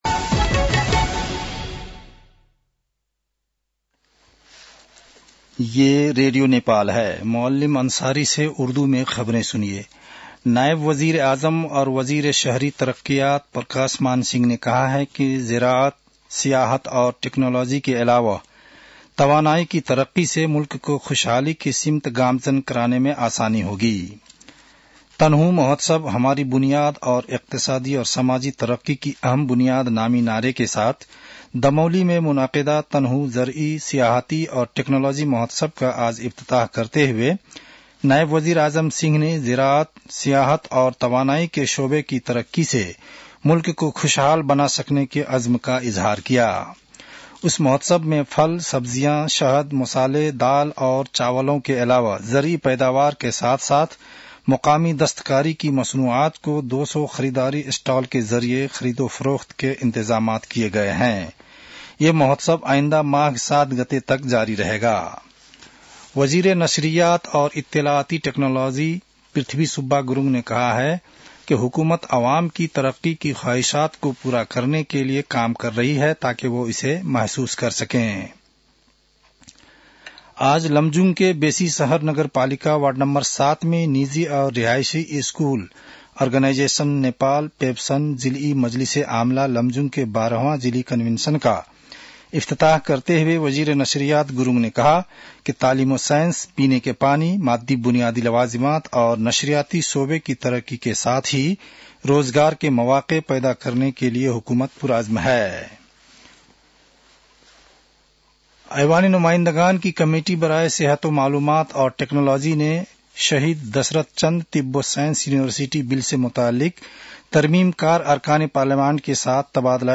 उर्दु भाषामा समाचार : २६ पुष , २०८१
Urdu-news-9-25.mp3